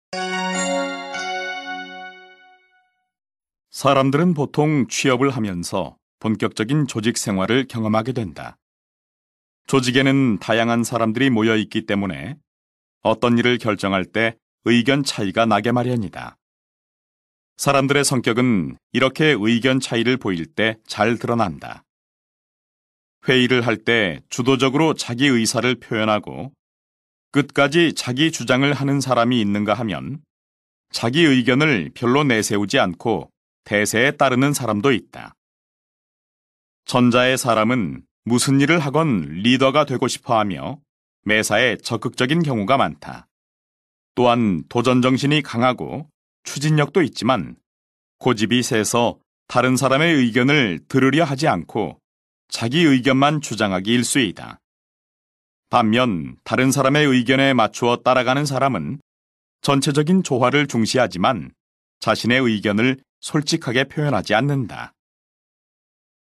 Đây là nội dung một bài đọc tuy nhiên cũng có audio nghe, nếu bạn có thời gian hãy nghe cách họ đọc ngắt nghỉ nội dung sẽ đọc dịch dễ hơn.